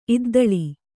♪ iddaḷi